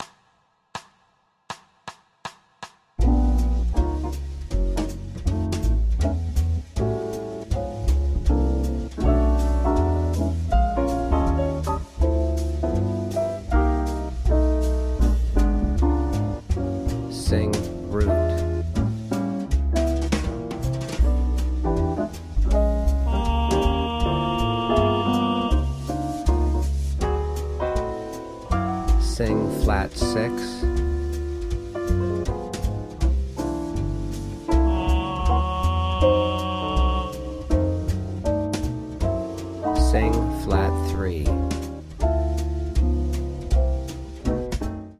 • Bb Rhythm Jazz Singing Tenor